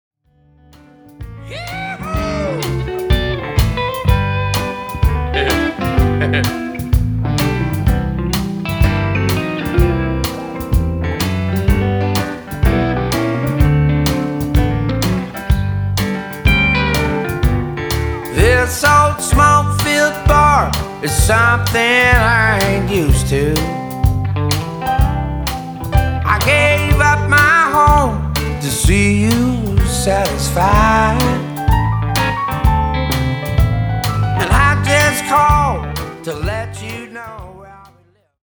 Vocal & Guitar
Recorded at Tony’s Treasures Studio, Cadiz, Ohio.